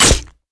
crawler_launcher_pickup1.wav